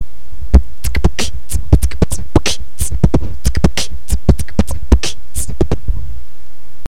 [b]-[tk][b][kch]-[t]-[b][tk][b][t]-[b][kch]-[tss]-[b]
Группа символов без без тире произносится тупо подряд))
это открытый хай-хэт, а t закрытый